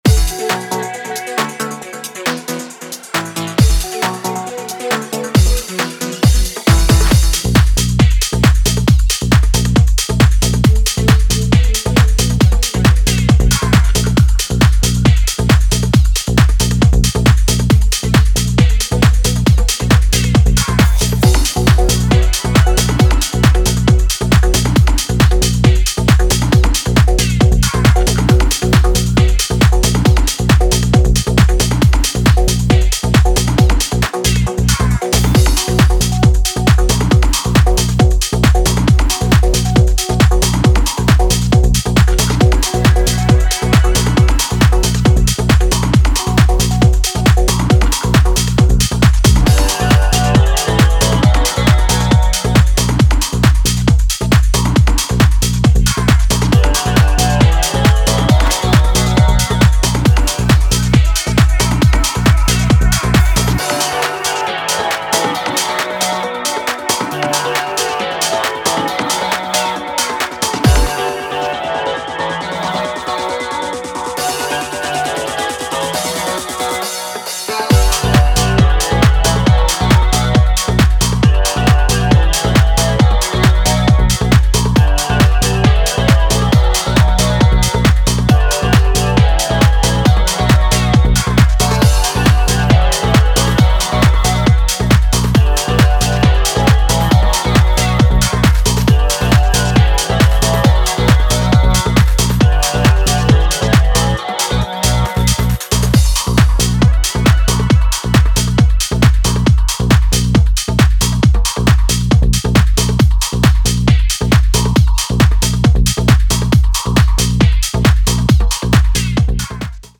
ボンゴ/コンガの軽快なグルーヴ、ヴォリュームたっぷりなローエンドのうねり、神秘的なヴォーカル